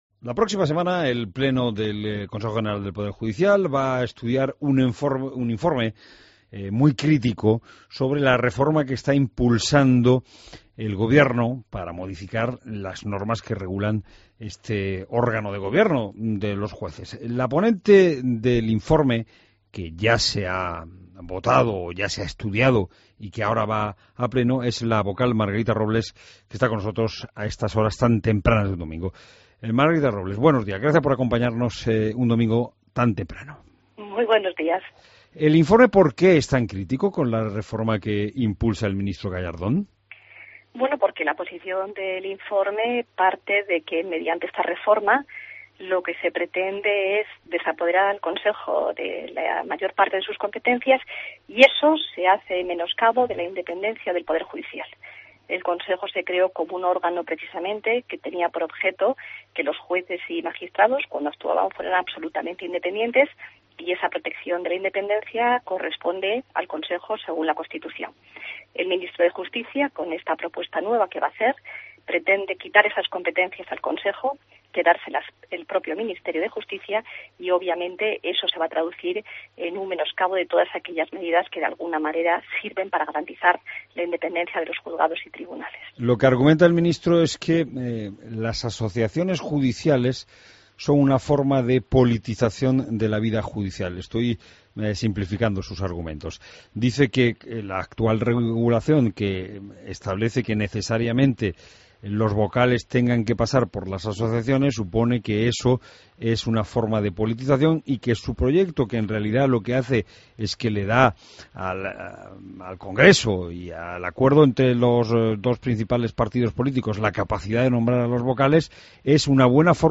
AUDIO: Escucha la entrevista a la vocal del CGPJ, Margarita Robles